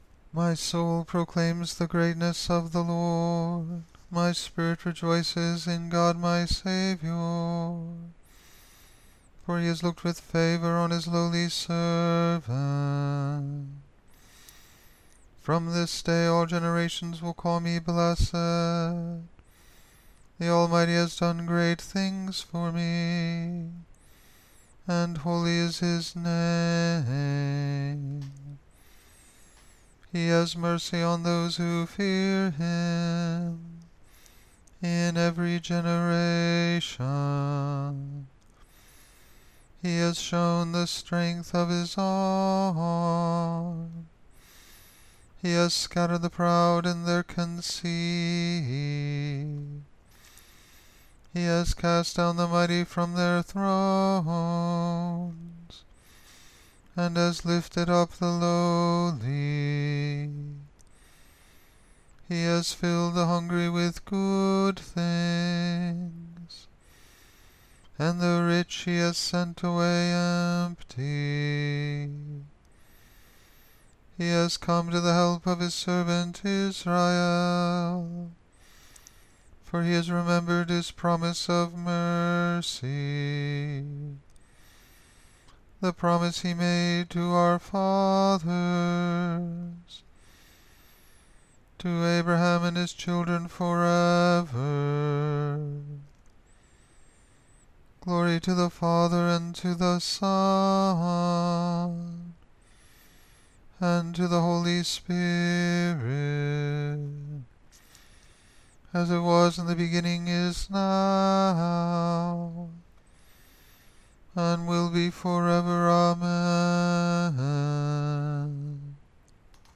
Caveat: this is not Gregorian Chant.